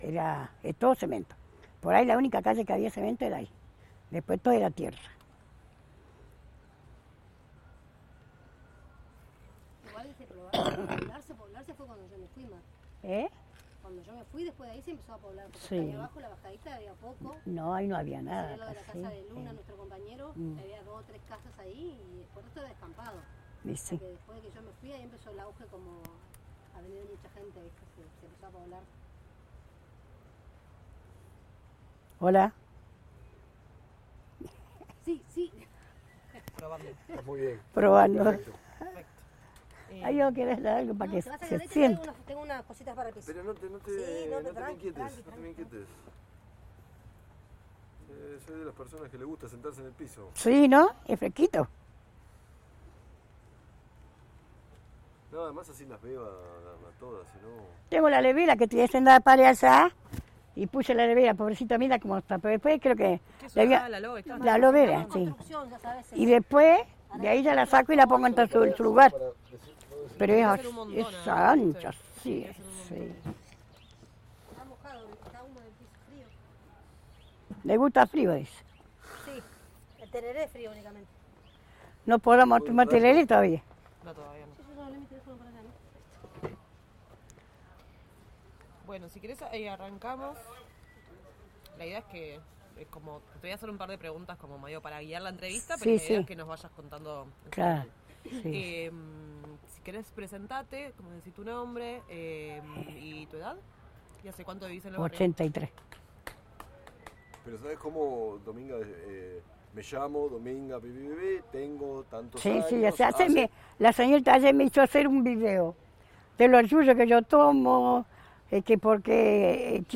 1 grabación sonora en soporte magnético